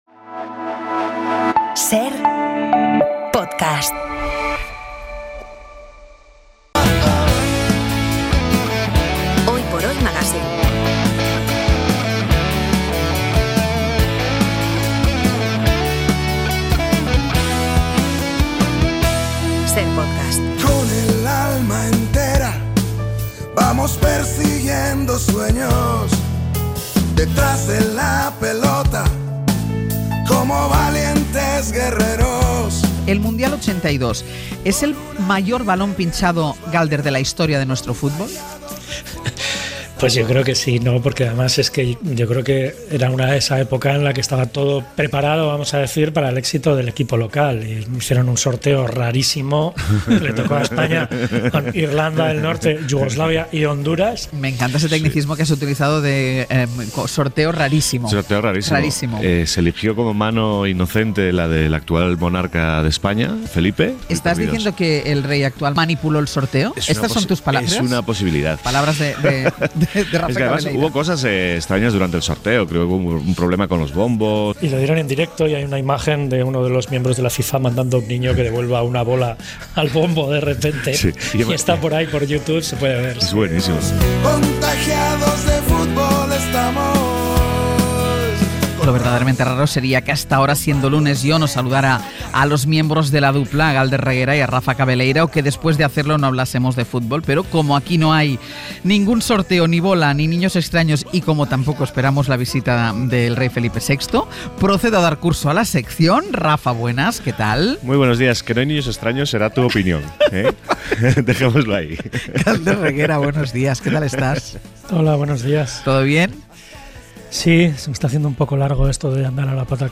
En La Dupla hemos recibido la visita del entrenador Pepe Mel para preguntarnos cómo es la vida de un entrenador cuando está a la espera de destino, bien porque al empezar la temporada ha sido destituido o bien porque todavía no ha sido contratado este año por ningún equipo.